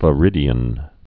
(və-rĭdē-ən)